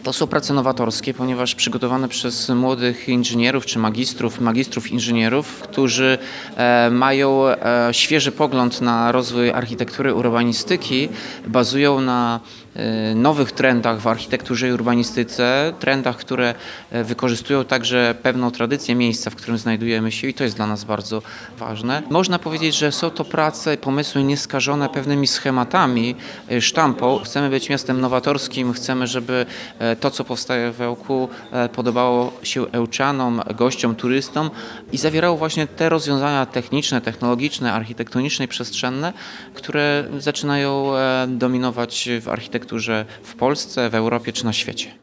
-Są to nowatorskie, nieskażone schematami prace- chwalił propozycje rozwiązań zgłoszone do konkursu prezydent Ełku Tomasz Andrukiewicz.